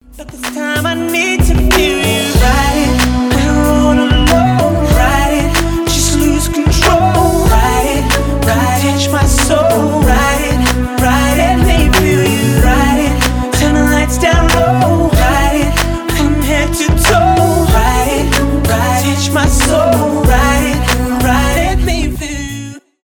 поп , хип-хоп , соул , rnb